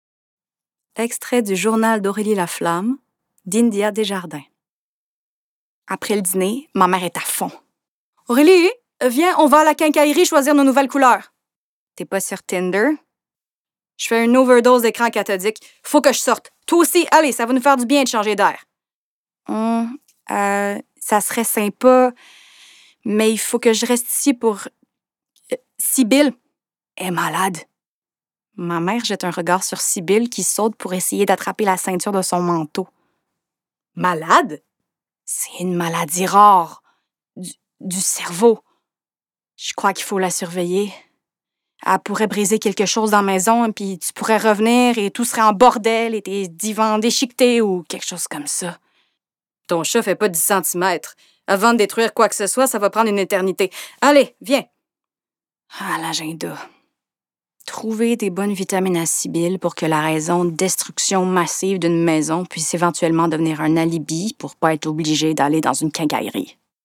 Âge naturelle de la voix Jeune adulte
Timbre Médium - Grave - Petit grain chaleureux
Le journal d'Aurélie Laflamme - Plusieurs personnage (Aurélie, mère,narratrice) - Inspirante - Québécois familier / Livre-audio